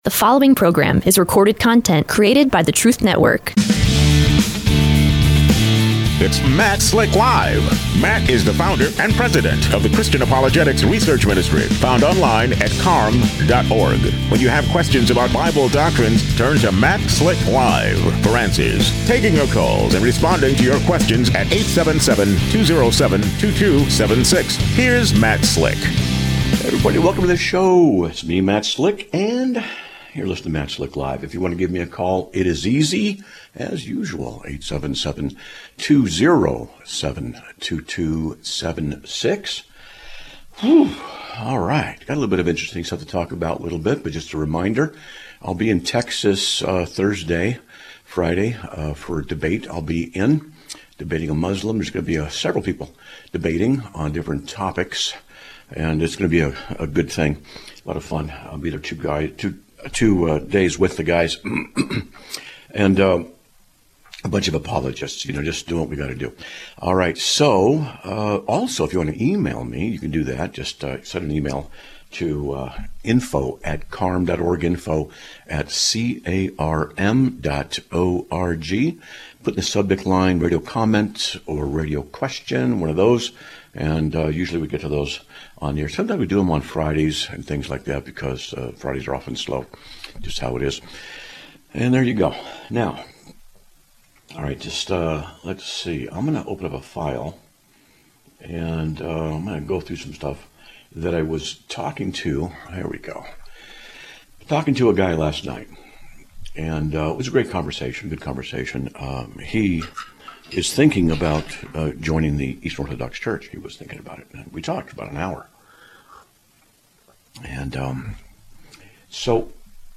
Live Broadcast of 02/03/2026
A Caller Asks About The Millenium